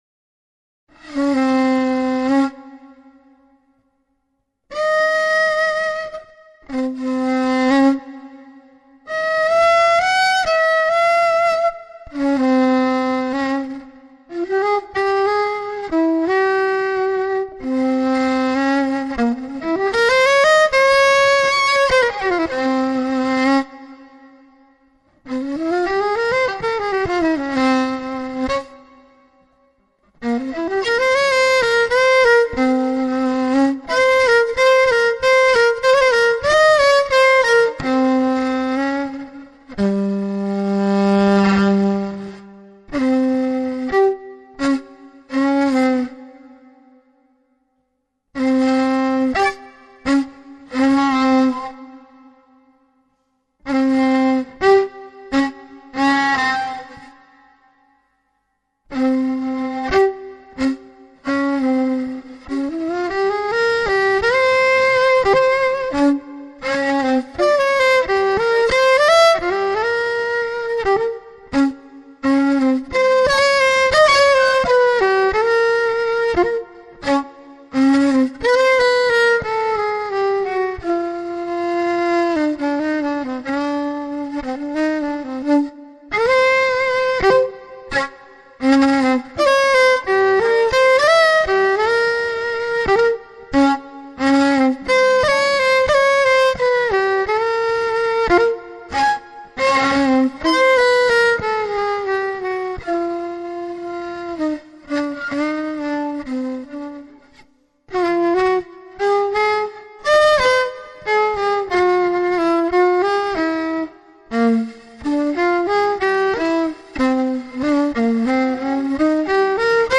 violin, vocals, viola, mandolin, guitars, percussion
in Genova, Italy, on September 2006